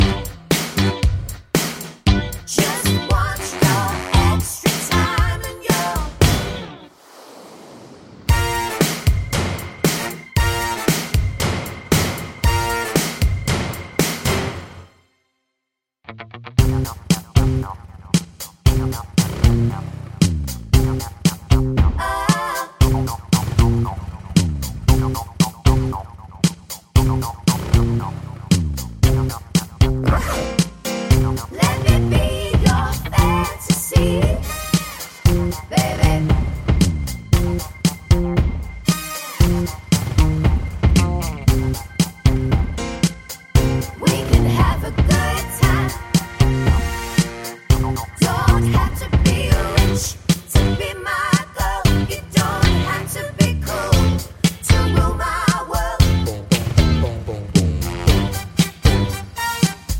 no Backing Vocals Pop (1980s) 3:36 Buy £1.50